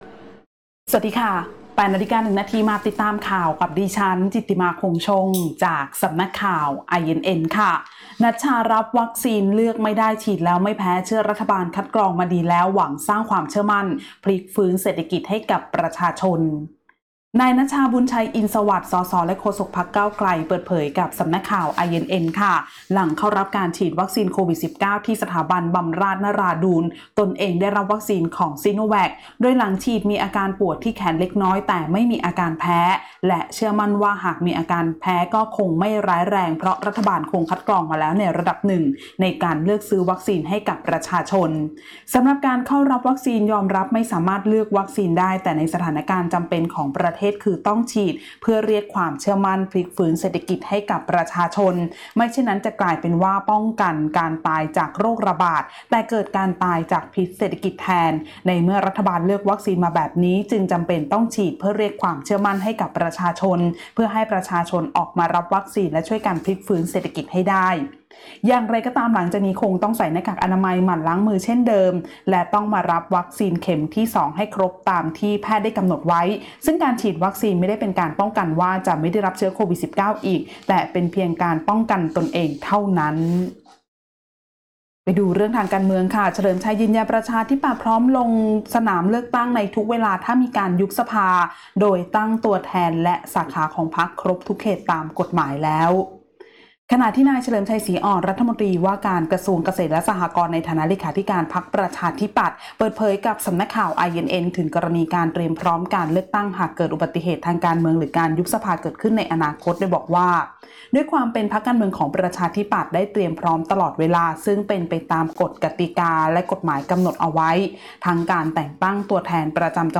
คลิปข่าวต้นชั่วโมง
ข่าวต้นชั่วโมง 08.00 น.